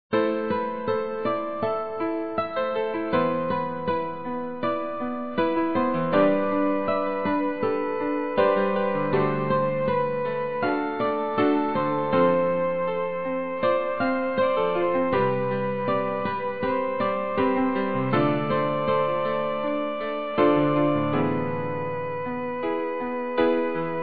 Peut se lire en boucle.